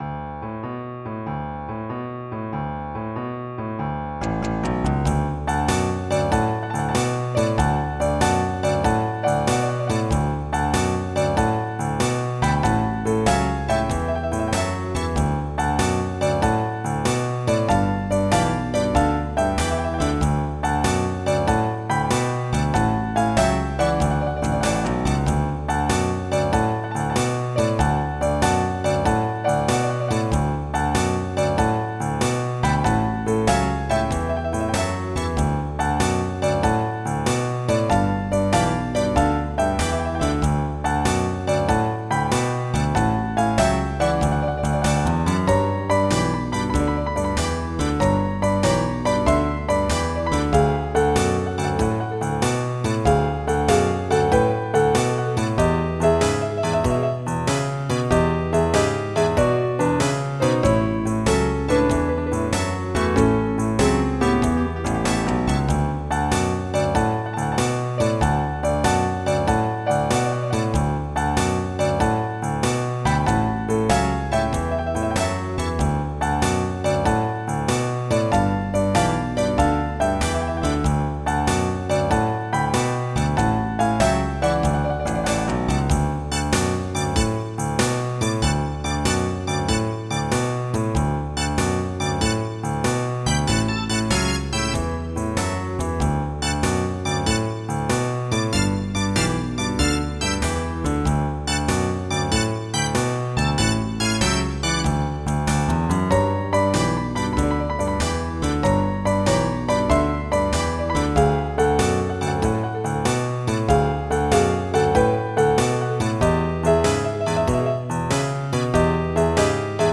Jazz
MIDI Music File
General MIDI